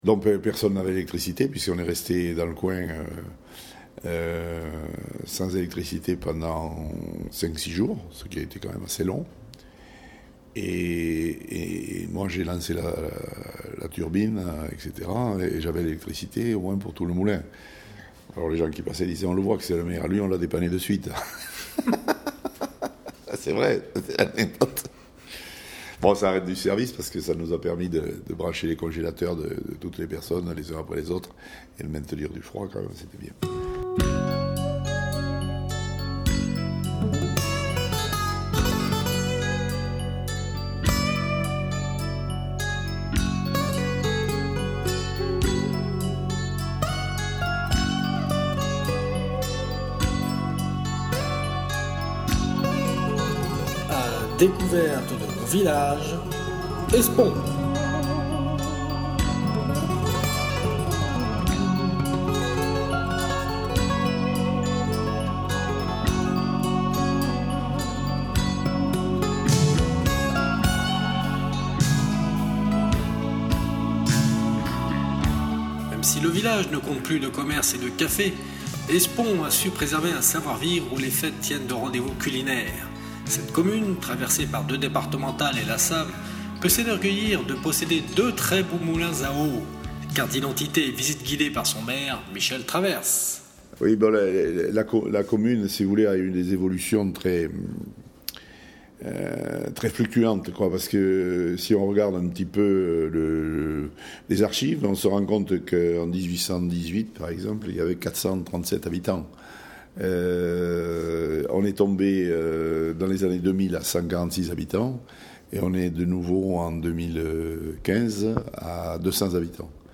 Première partie du reportage sonore sur Espaon